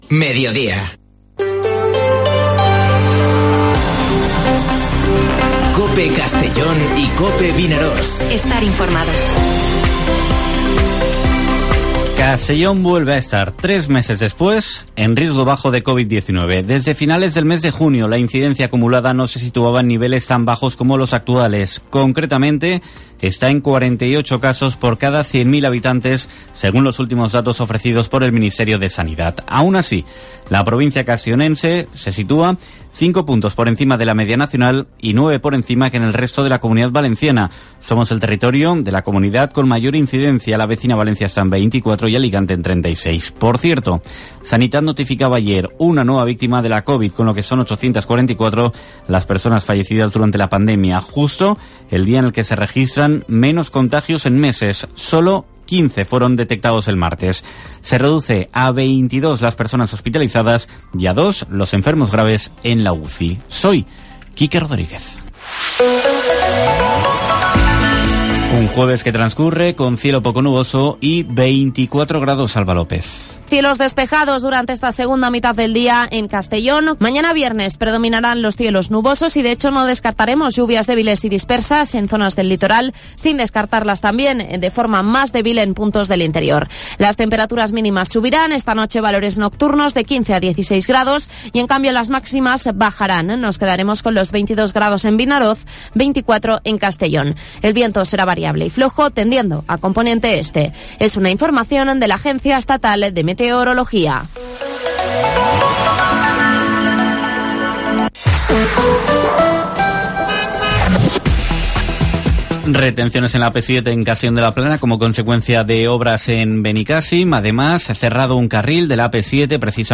Informativo Mediodía COPE en la provincia de Castellón (07/10/2021)